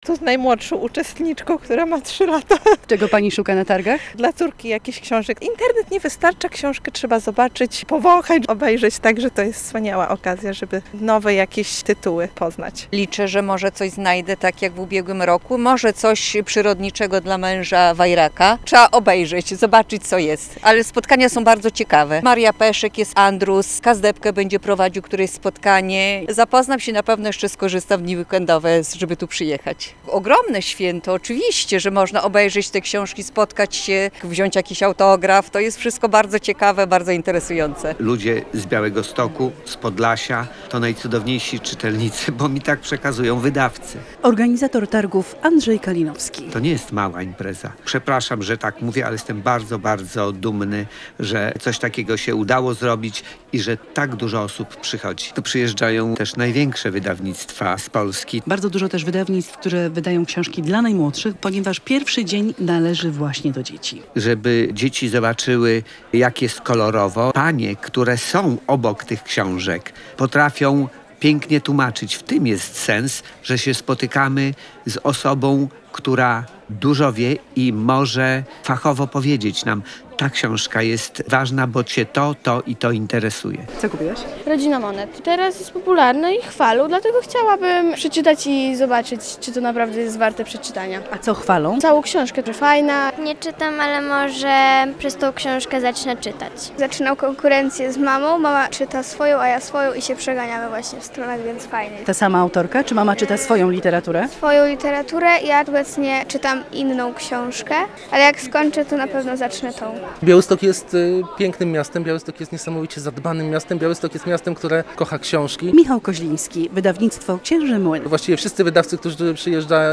Jubileuszowe Targi Książki w Białymstoku - relacja